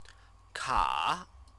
chinese_characters_ka_ka.mp3